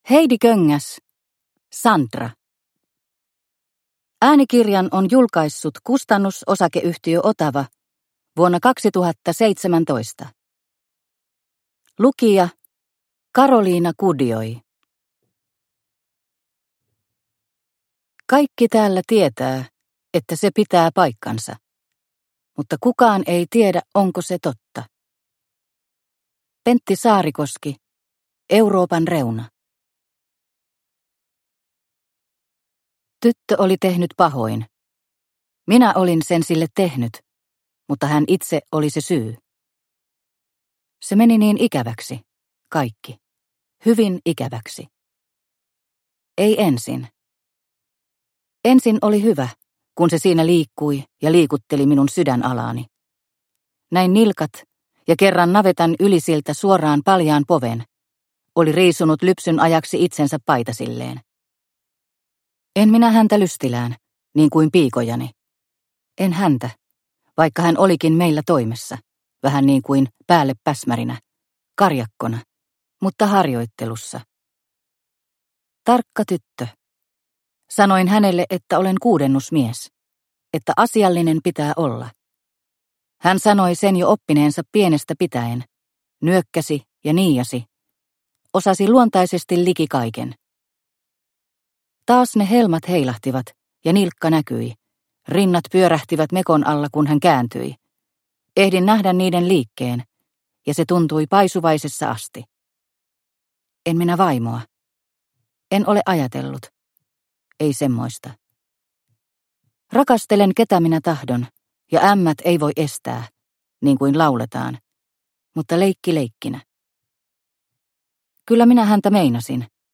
Sandra – Ljudbok – Laddas ner